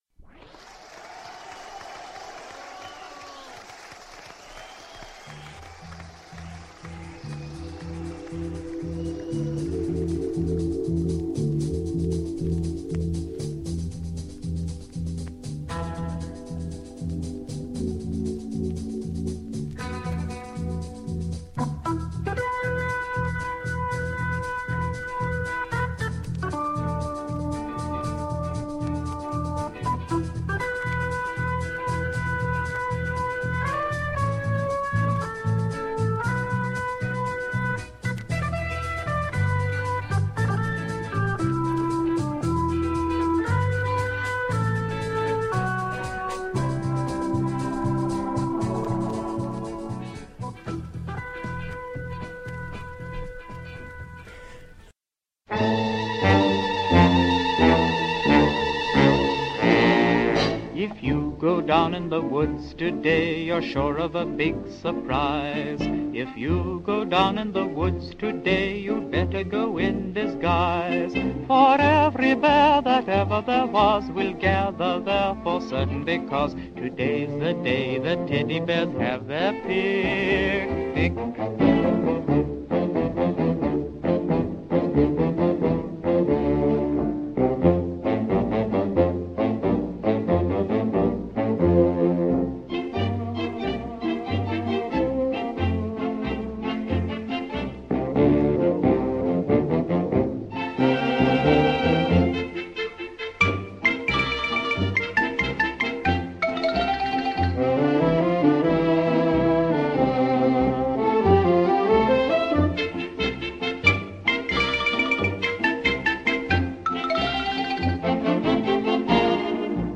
An interview originally aired on the BBC in 1994 with an author who said he wrote five minutes into the future, and whose last novel, Kingdom Come, is strikingly evocative of the current US Presidential campaign.